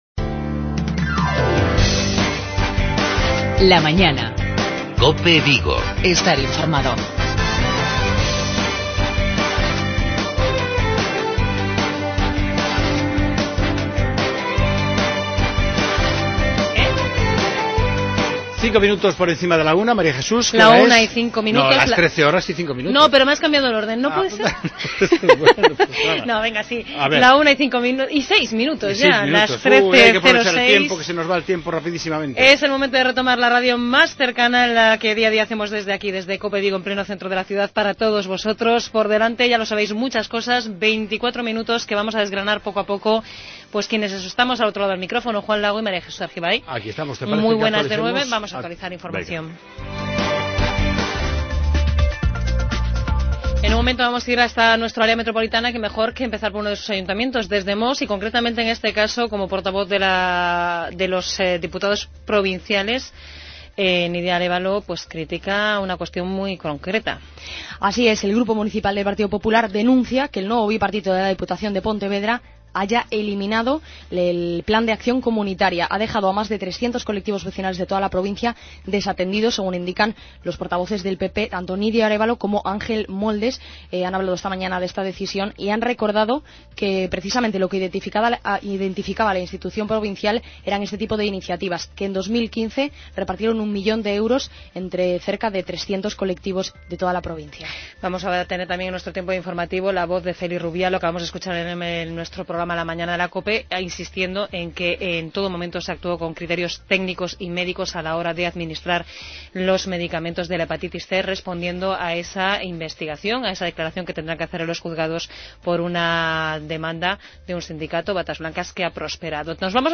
Finalizamos el programa de hoy martes con una entrevista en profundidad a Ethel Vázquez, Conselleira de Infraestructuras e Vivenda de la Xunta de Galicia.